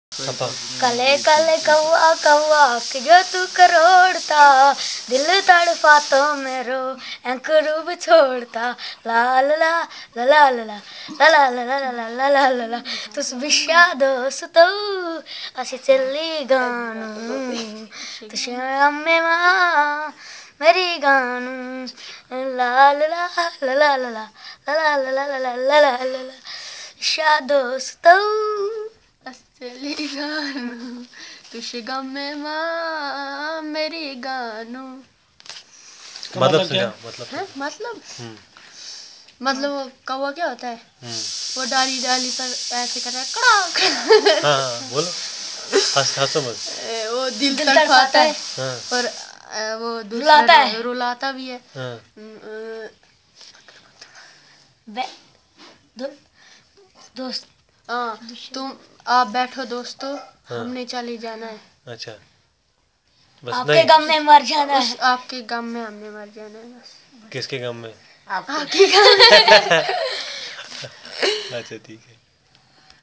Performance of love song